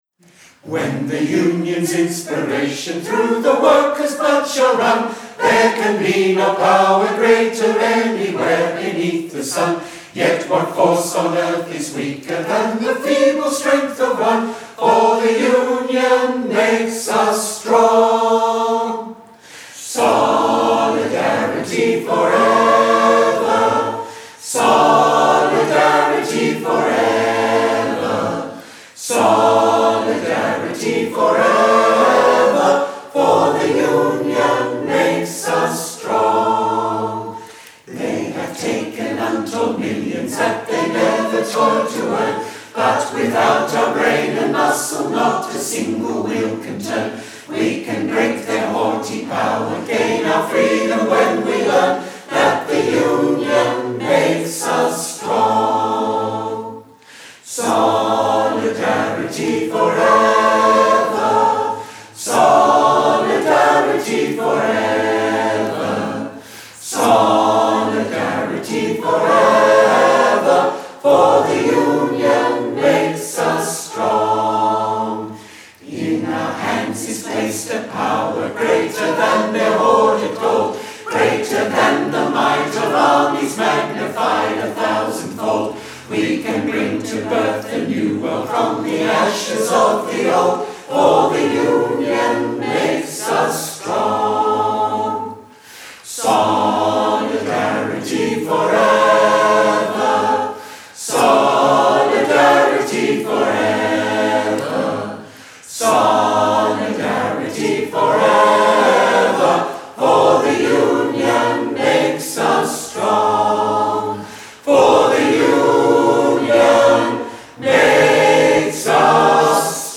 The recordings - The Victorian Trade Union Choir
The Victorian Trade Union Choir very kindly recorded some of these songs for the exhibition A Nation Divided: The Great War and Conscription.